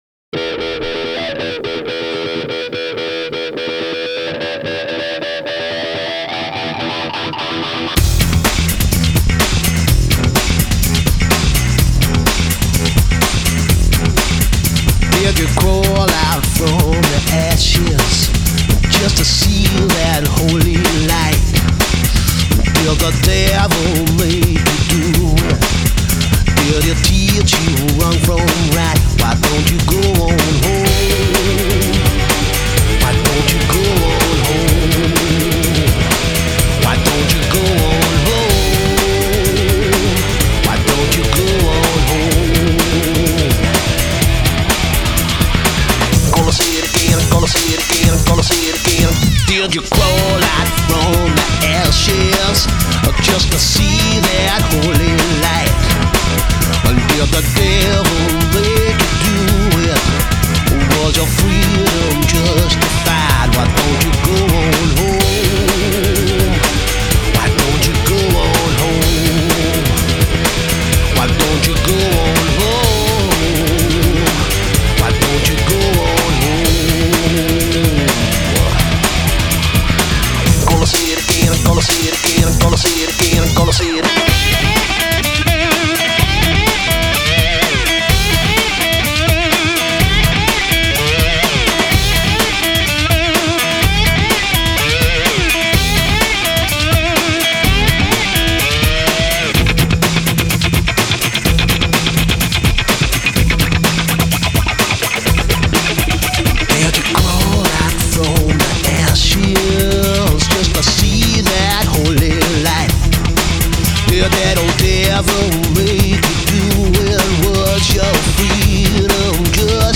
ExperimentalRock / Electronic / DrumNBass